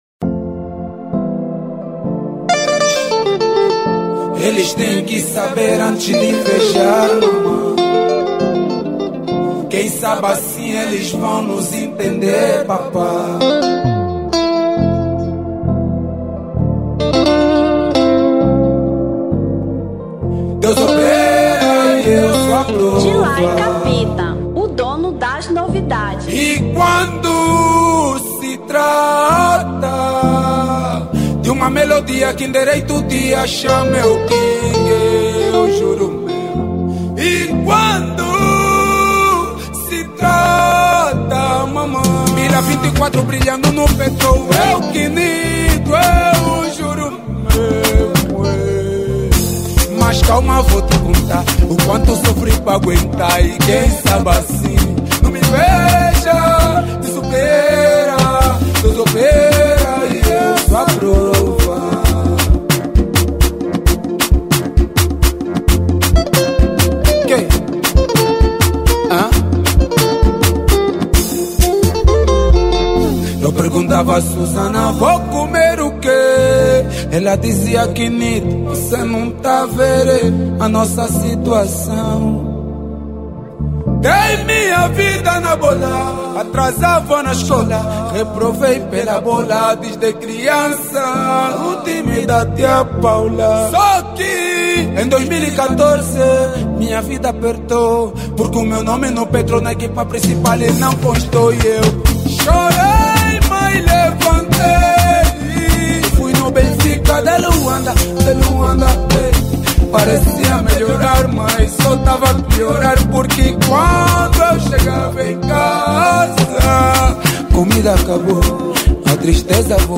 Kuduro 2023